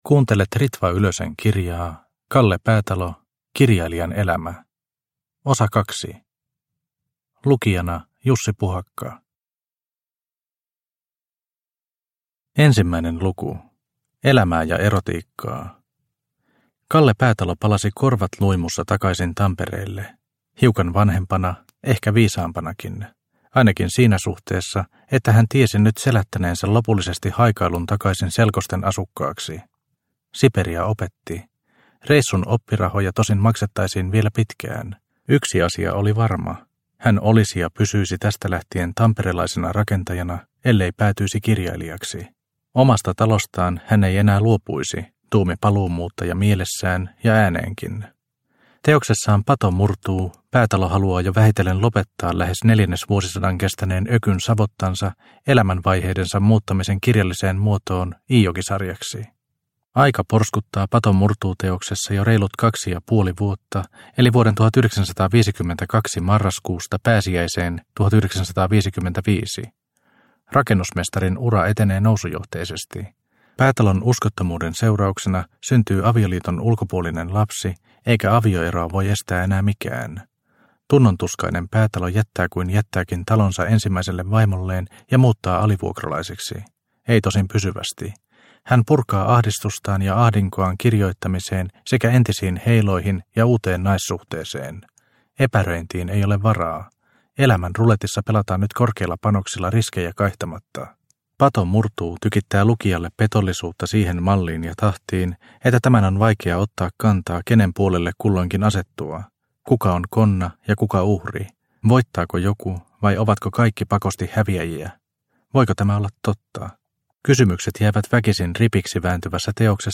Kalle Päätalo – Ljudbok – Laddas ner